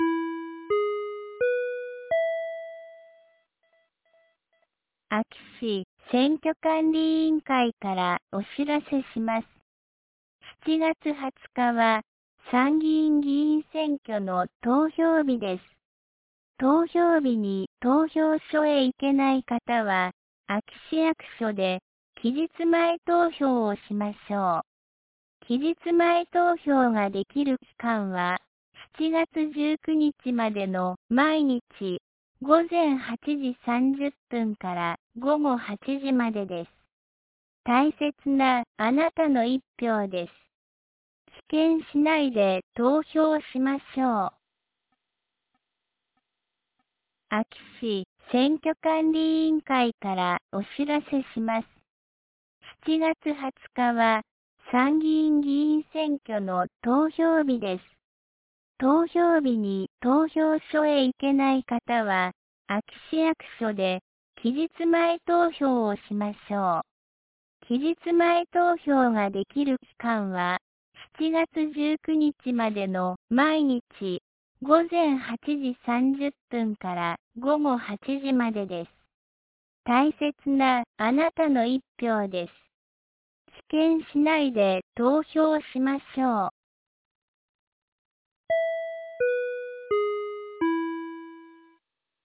2025年07月07日 12時46分に、安芸市より全地区へ放送がありました。